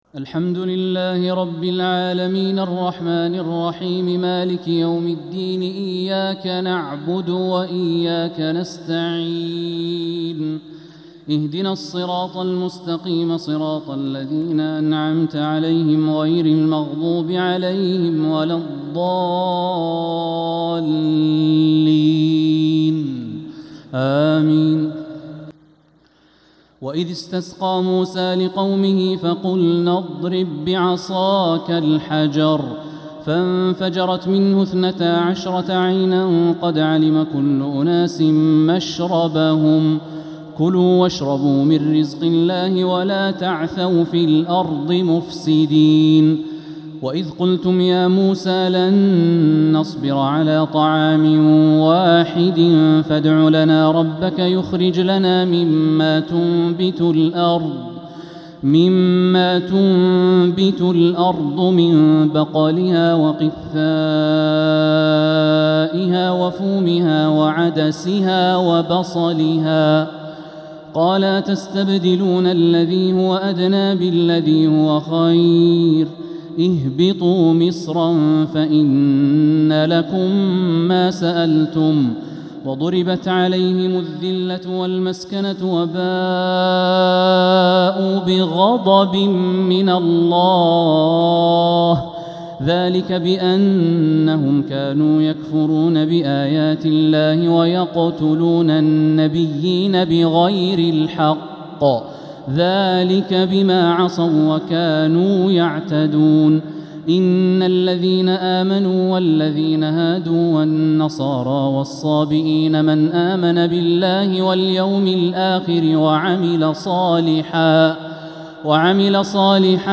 تراويح ليلة 1 رمضان 1447هـ من سورة البقرة (60-91) | Taraweeh 1st night Ramadan 1447H > تراويح الحرم المكي عام 1447 🕋 > التراويح - تلاوات الحرمين